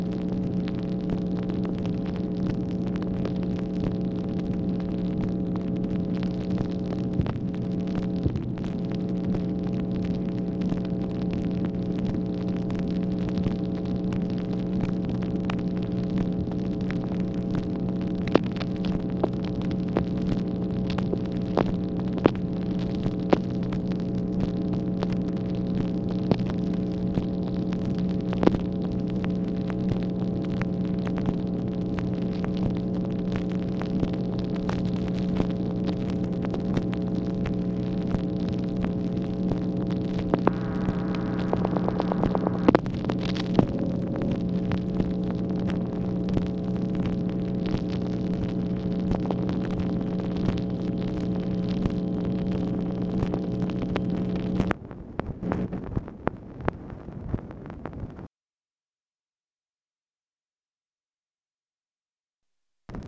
MACHINE NOISE, April 30, 1965
Secret White House Tapes | Lyndon B. Johnson Presidency